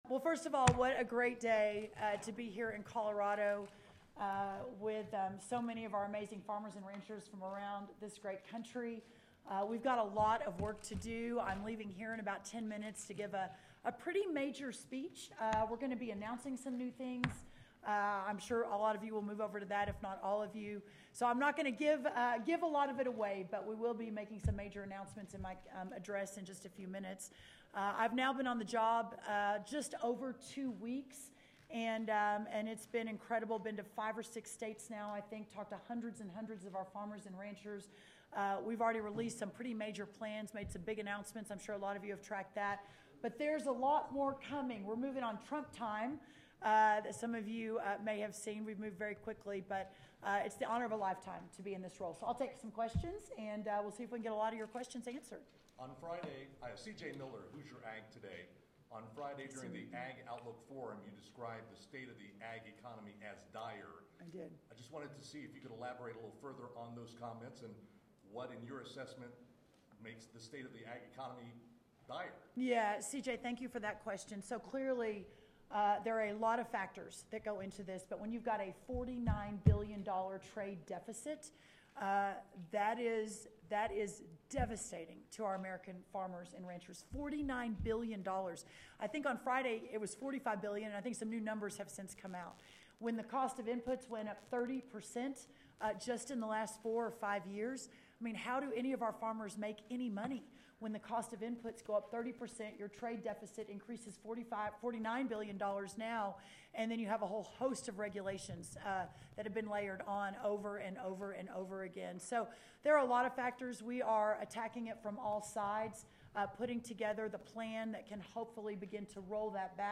DENVER, Colorado – United States Department of Agriculture (USDA) Secretary Brooke Rollins addressed a crowd of over a thousand farmers at the 2025 Commodity Classic in Denver.
brooke-rollins-press-conference.mp3